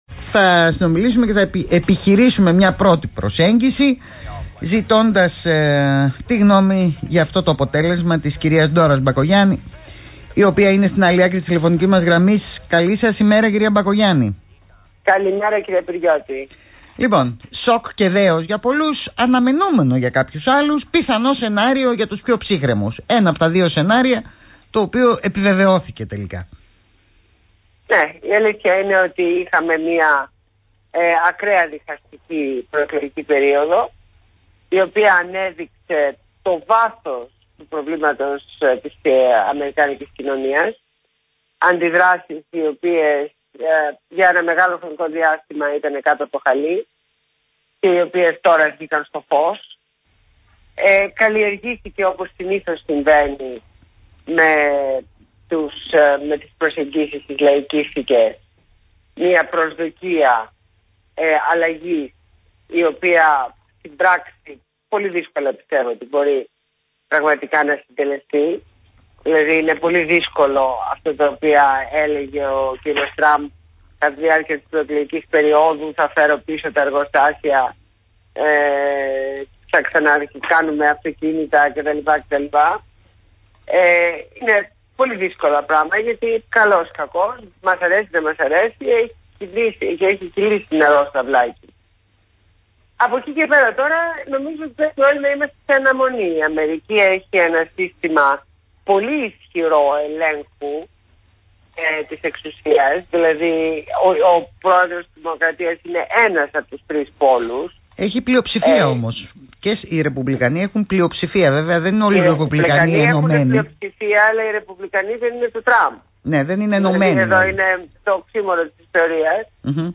Συνέντευξη στο ραδιόφωνο Παραπολιτικά 90,1fm